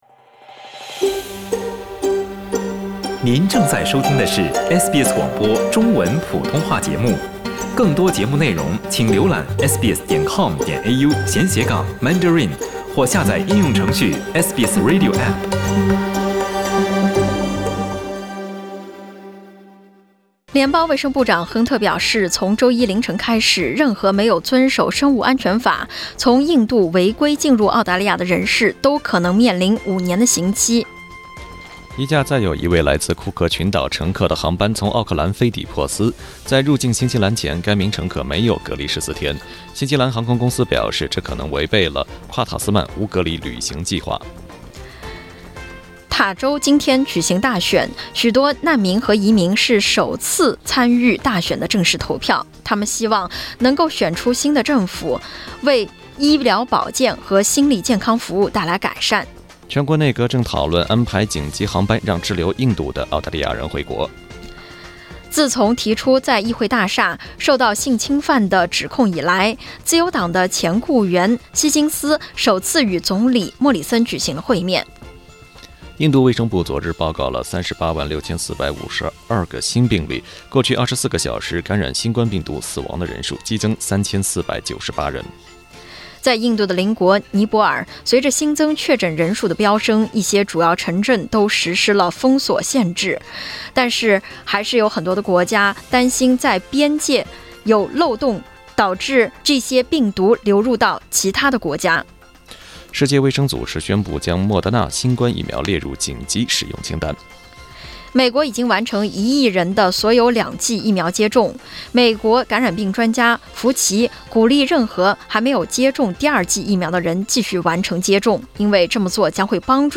SBS早新聞（5月1日）